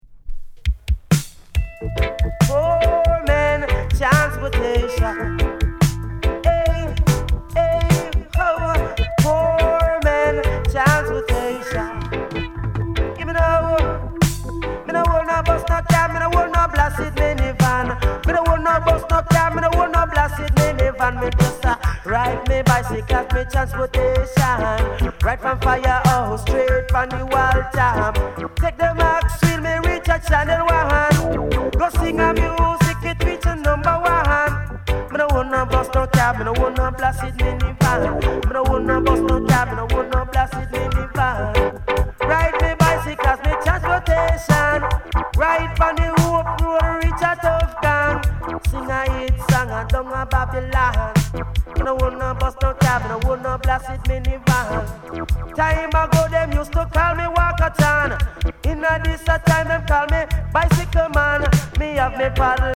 DIGI ROOTS